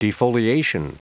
Prononciation audio / Fichier audio de DEFOLIATION en anglais
Prononciation du mot defoliation en anglais (fichier audio)